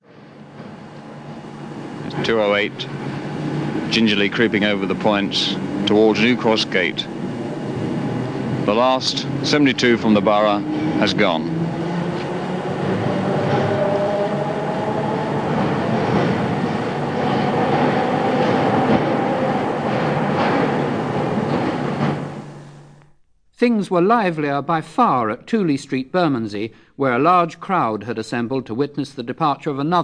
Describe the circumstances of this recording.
Londons Last Trams Stage 4 Live recordings Stage 4 Borough, Bermondsey, Greenwich and Waterloo 10 July 1951